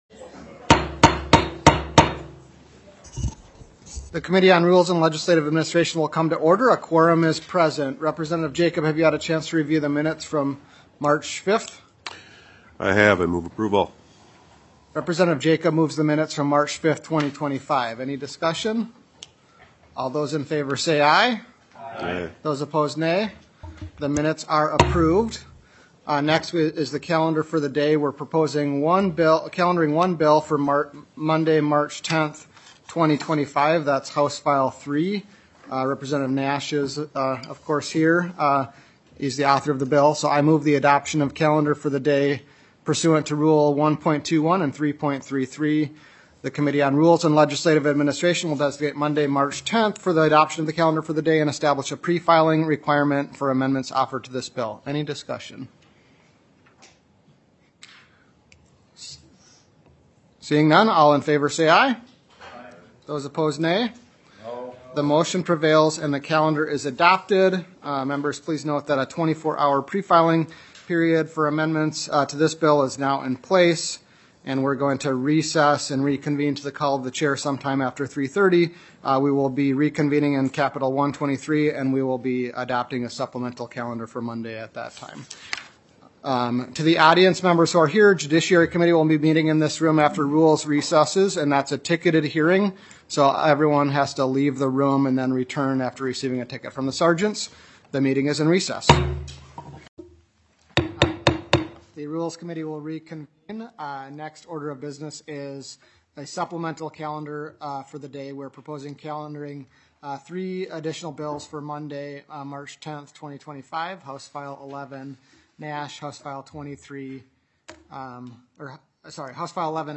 Meetings are listed by date with the most recent meeting at the top.
*Note: Committee will recess and reconvene at the Call of the Chair in Capitol Room 123.